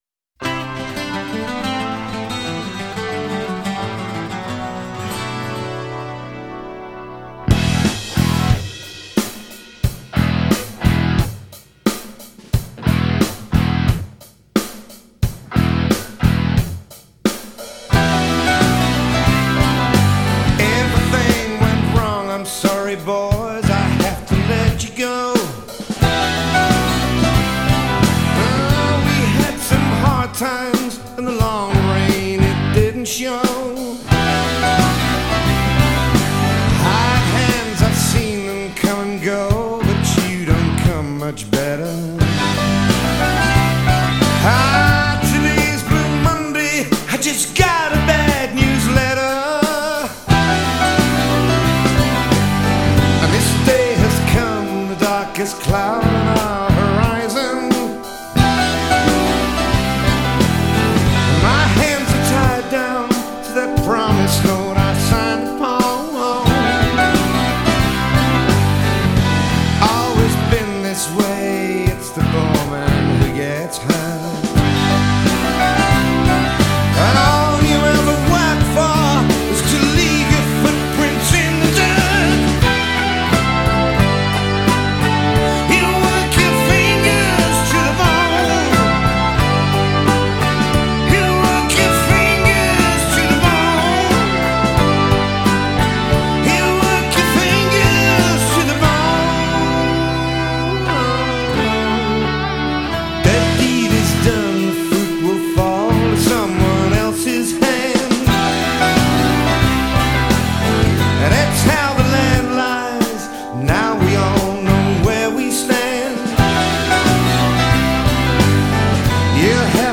guitarist